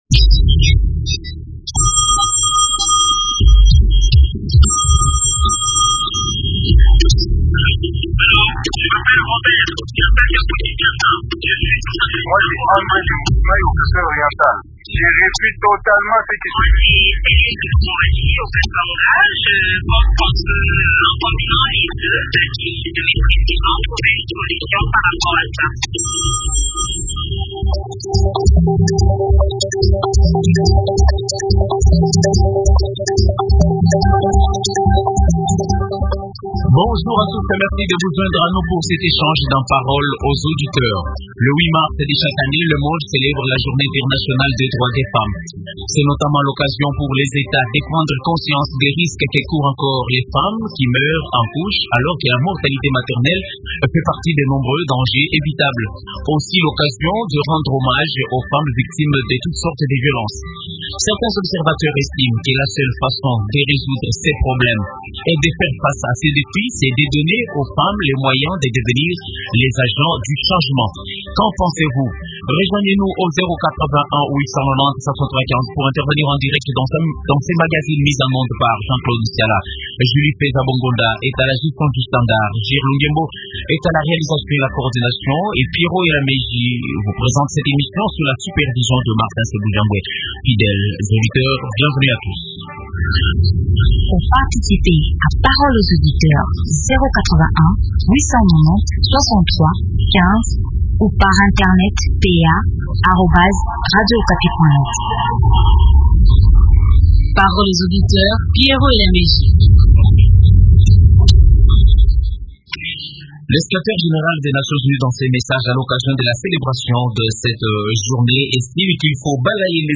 Invitée: Ministre du genre, famille, femmes et enfants.